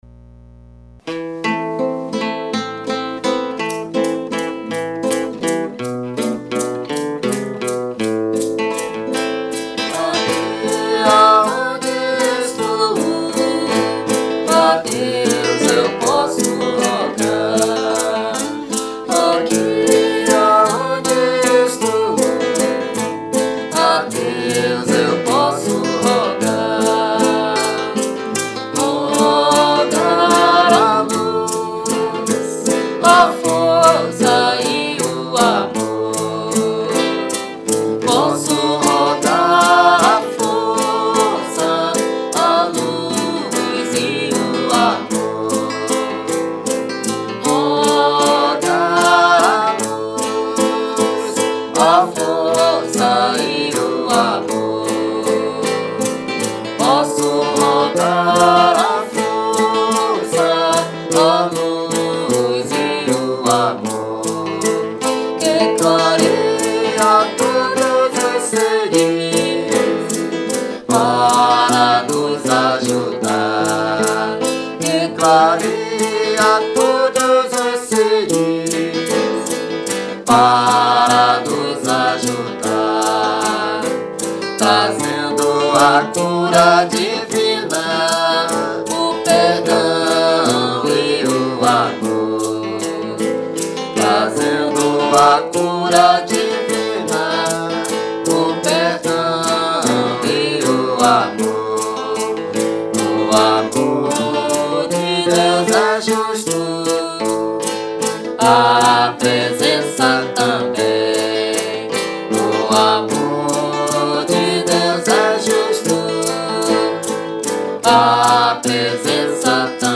Recording source: Official Version
valsa
waltz